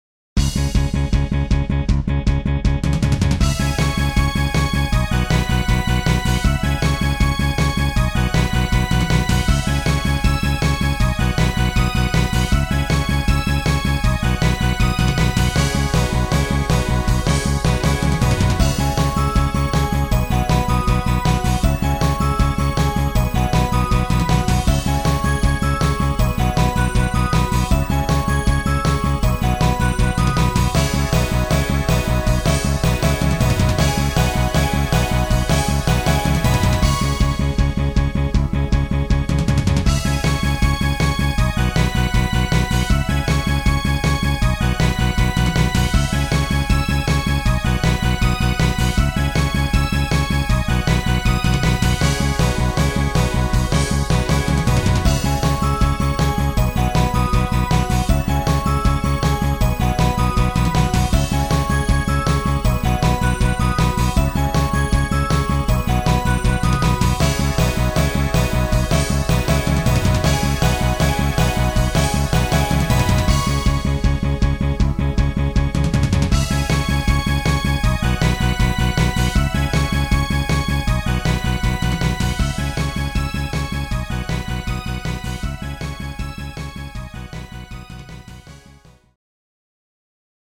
原曲はもの悲しい雰囲気ですが、戦闘をイメージして派手にしました。